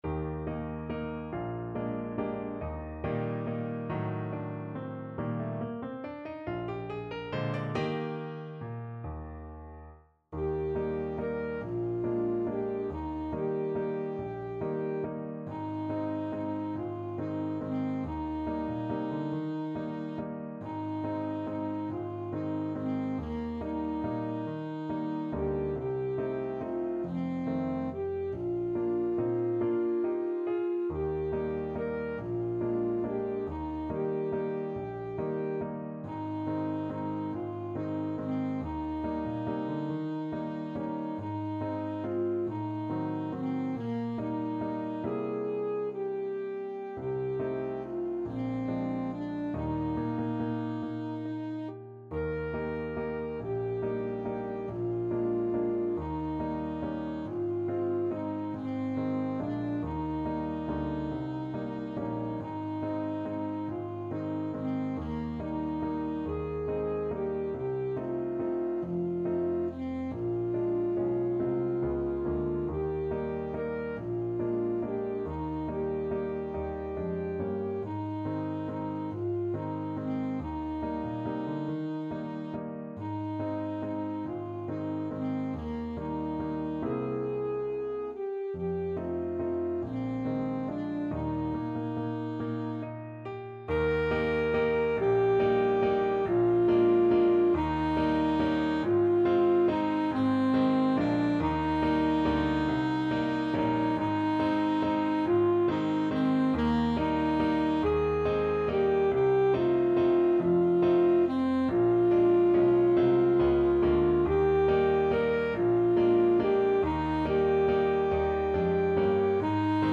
Alto Saxophone
3/4 (View more 3/4 Music)
~ = 140 Tempo di Valse